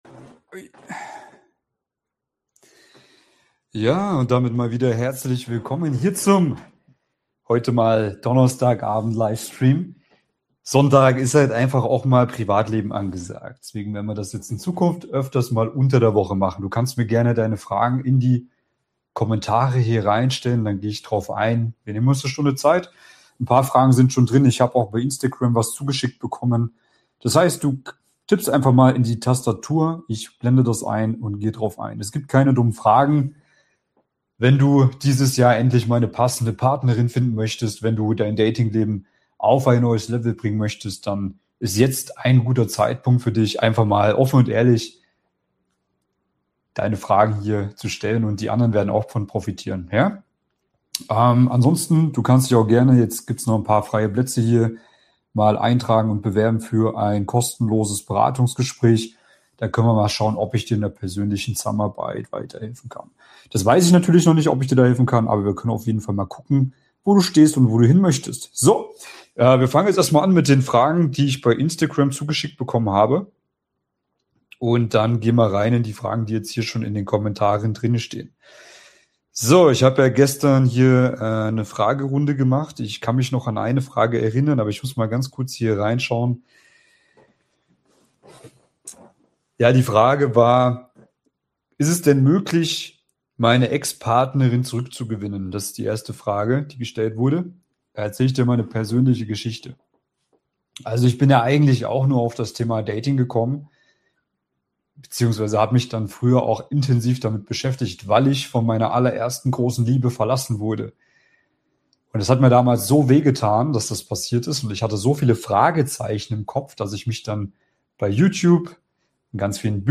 Beschreibung vor 2 Tagen In diesem YouTube-Livestream beantworte ich live eure Fragen rund um Dating, Partnersuche, Frauen verstehen und moderne Mann-Frau-Dynamiken.